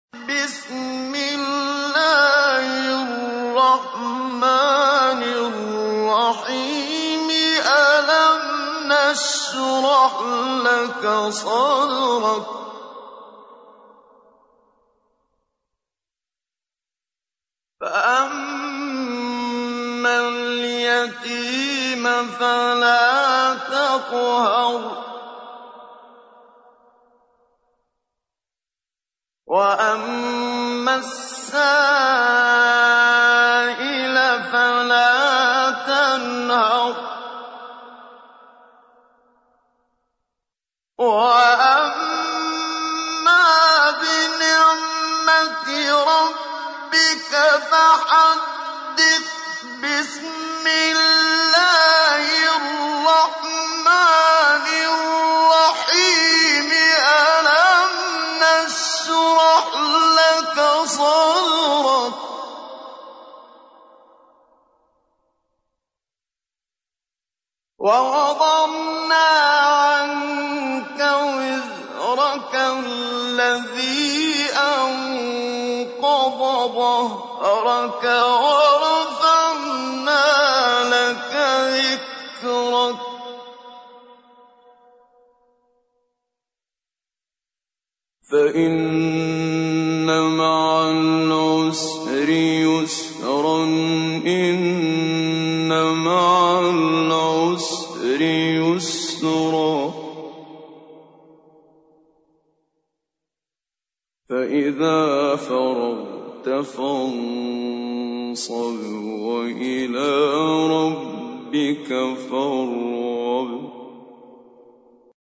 تحميل : 94. سورة الشرح / القارئ محمد صديق المنشاوي / القرآن الكريم / موقع يا حسين